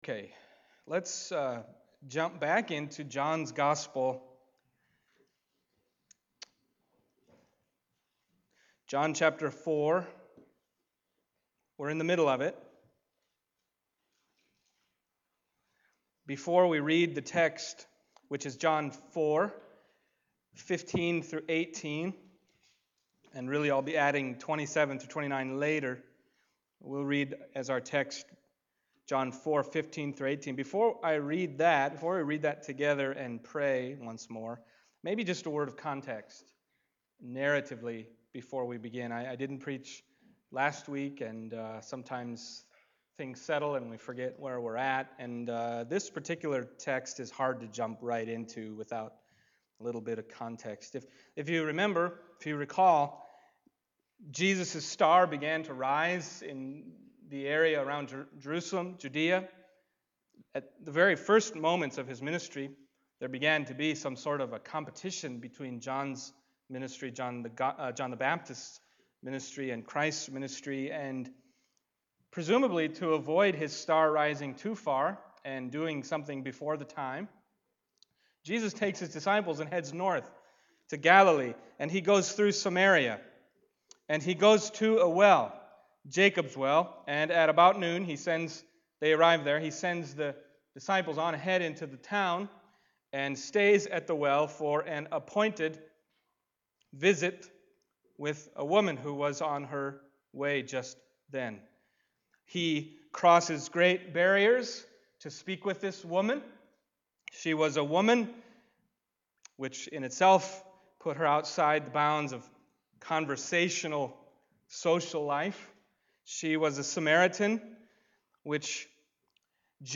John Passage: John 4:15-18, John 4:27-29 Service Type: Sunday Morning John 4:15-18